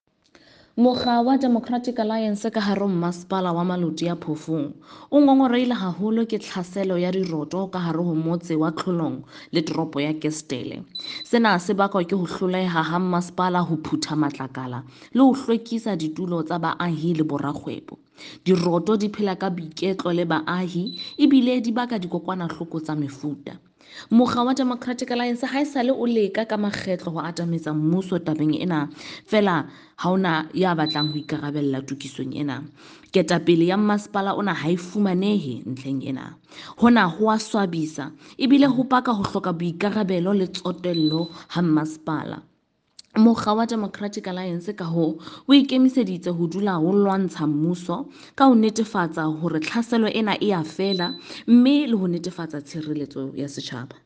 Sesotho by Karabo Khakhau MP.